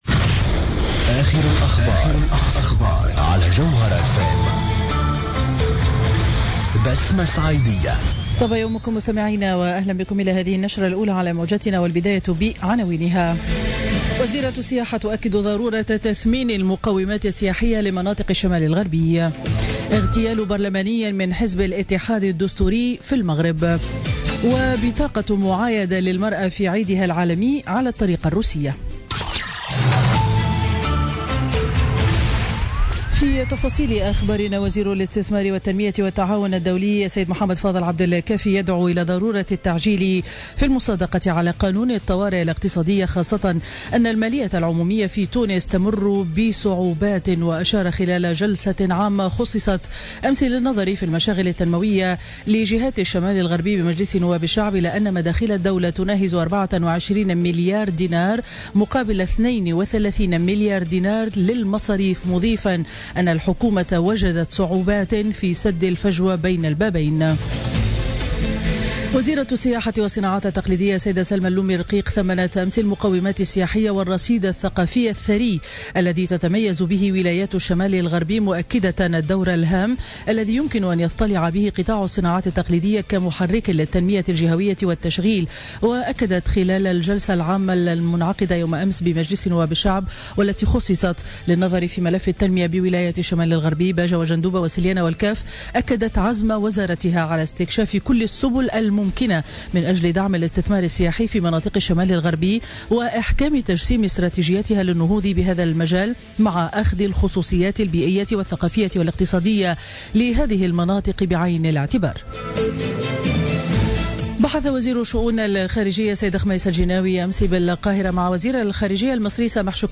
نشرة أخبار السابعة صباحا ليوم الإربعاء 8 مارس 2017